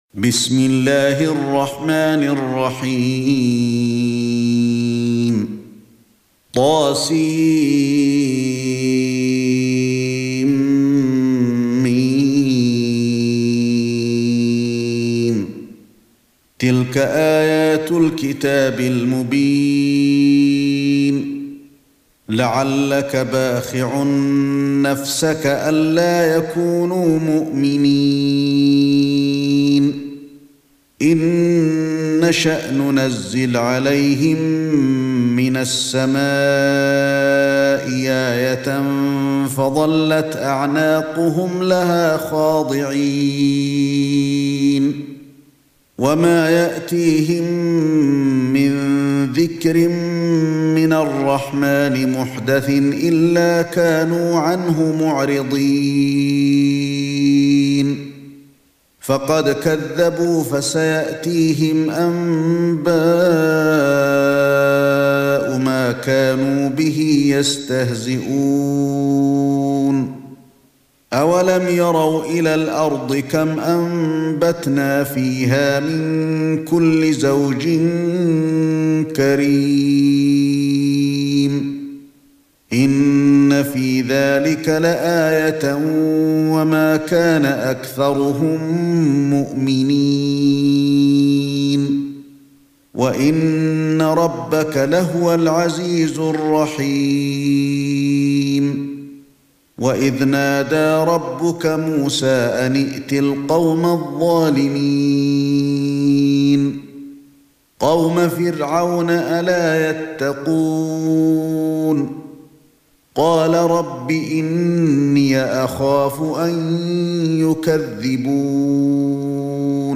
سورة الشعراء ( برواية قالون ) > مصحف الشيخ علي الحذيفي ( رواية قالون ) > المصحف - تلاوات الحرمين